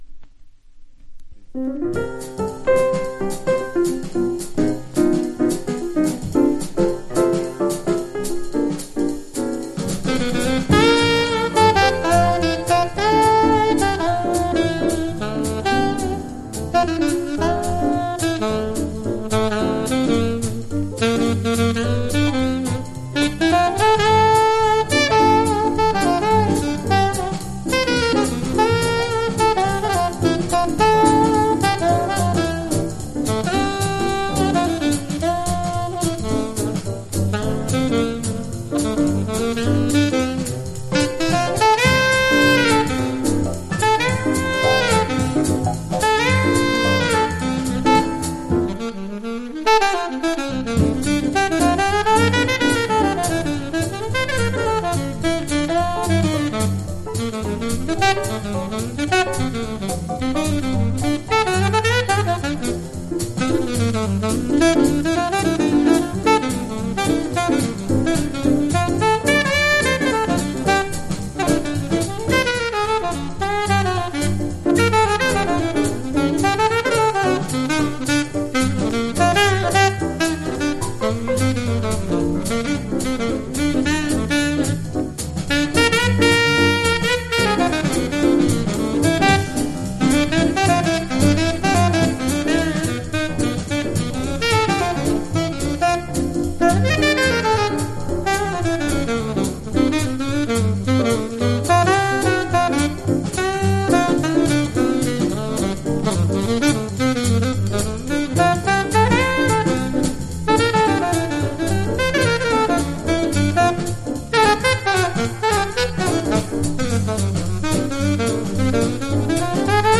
ワンホーンもの名盤